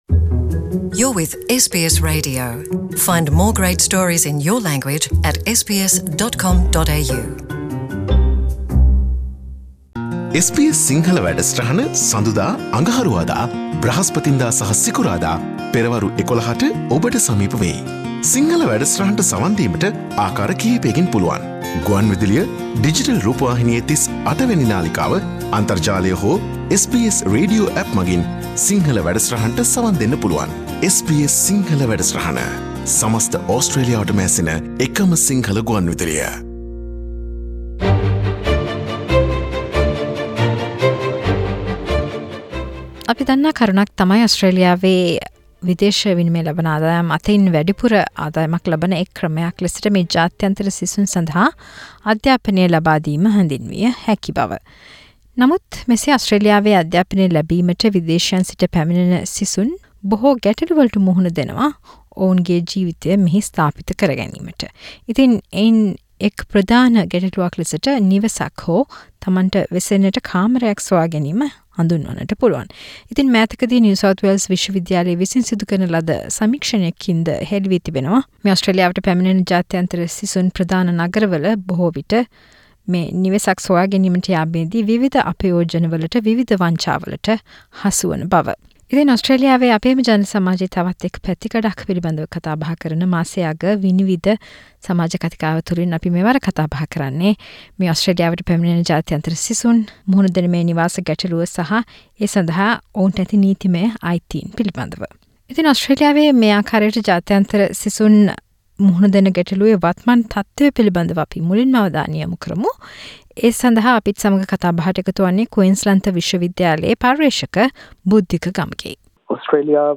SBS සිංහල "විනිවිද" සමාජ කතිකාව :ඕස්ට්‍රේලියාවේ අන්තර්ජාතික සිසුන් නිවෙස් කුලියට ගැනීමේදී මුහුණ දෙන ගැටළු හා ඒ සදහා ඔවුන්ට ඇති නීතිමය අයිතීන්